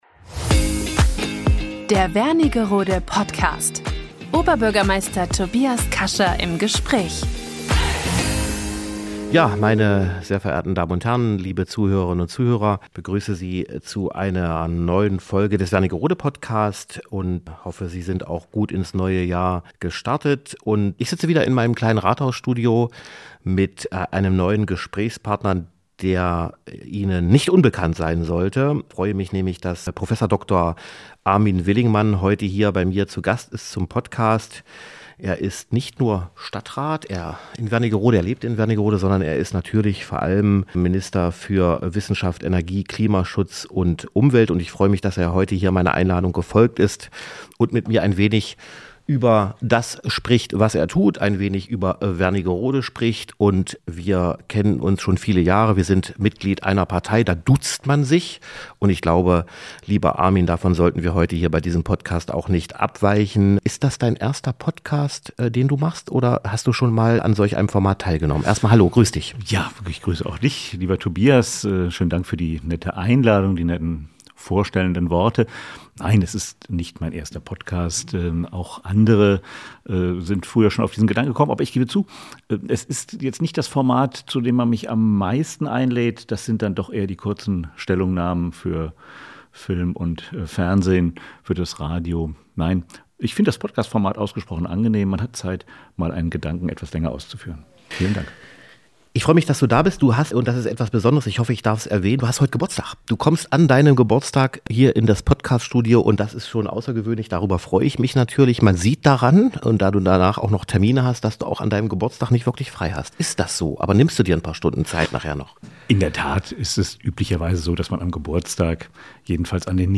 In der aktuellen Folge spricht Oberbürgermeister Tobias Kascha mit dem Minister für Wissenschaft, Energie, Klimaschutz und Umwelt des Landes Sachsen-Anhalt, Prof. Dr. Armin Willingmann.